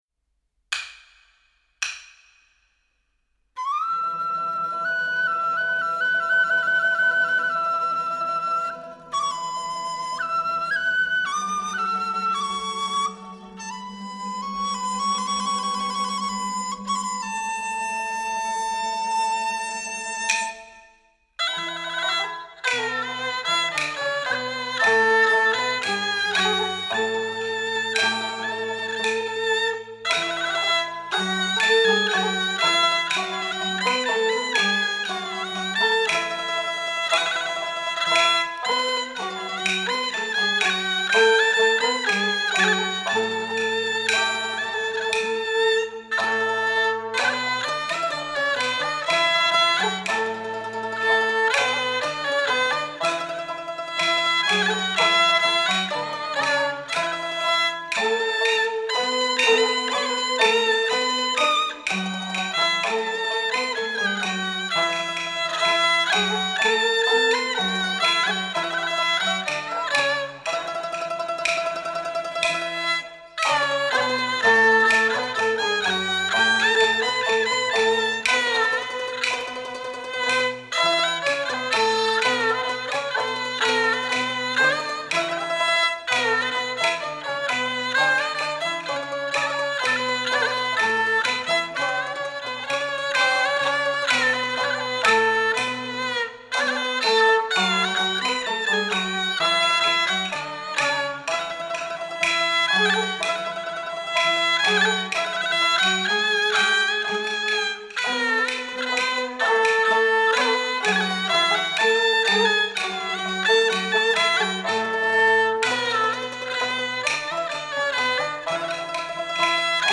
0031-京胡名曲庆赏元升.mp3